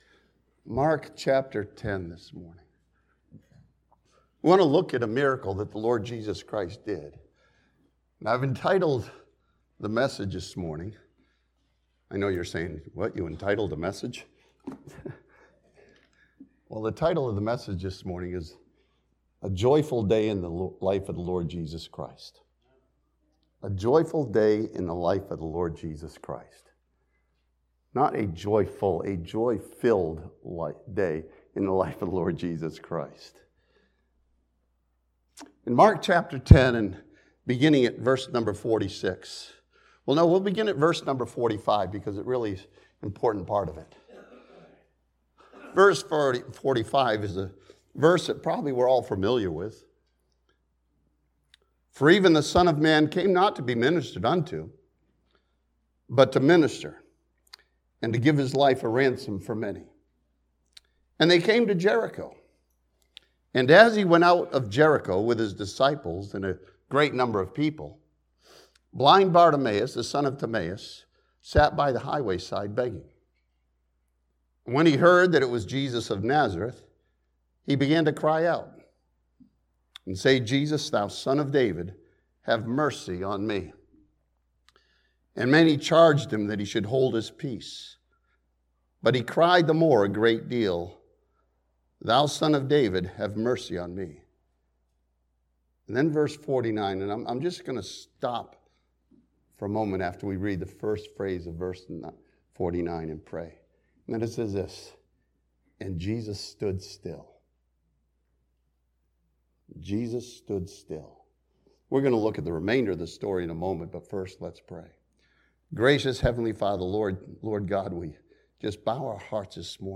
This sermon from Mark chapter 10 follows Jesus in a joy filled day as He heals the blind man.